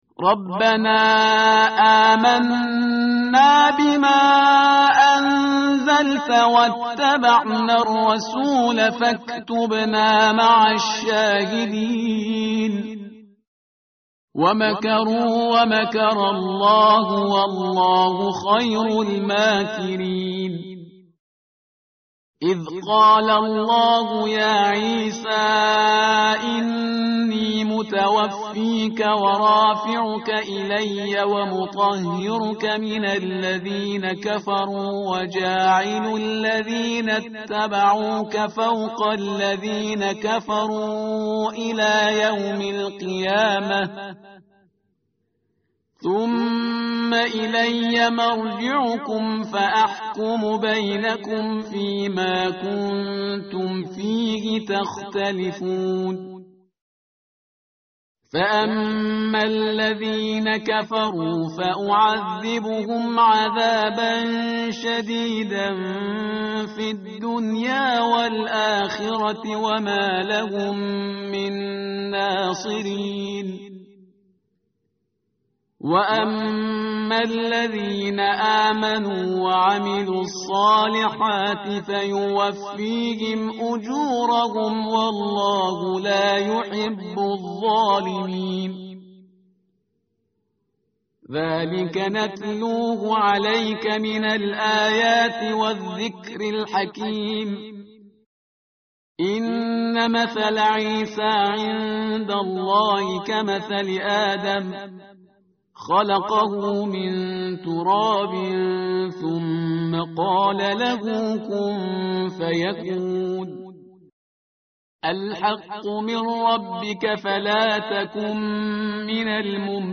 tartil_parhizgar_page_057.mp3